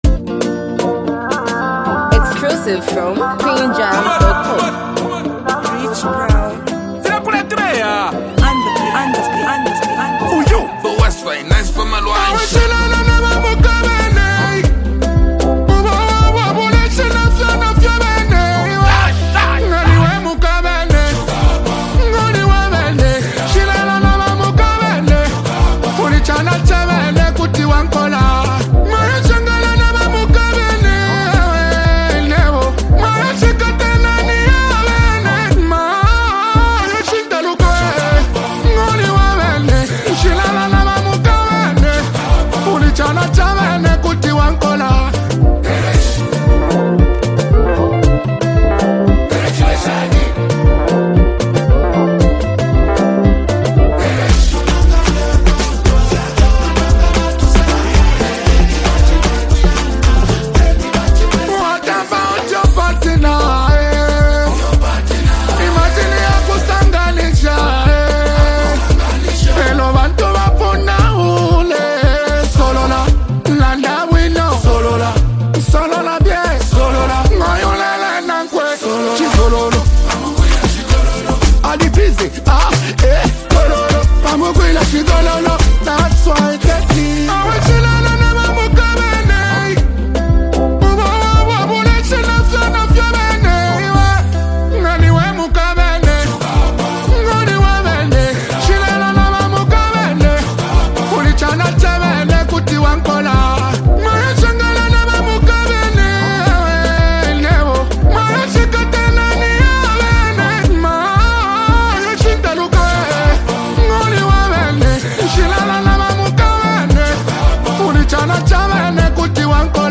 bringing in his well-known dancehall influence.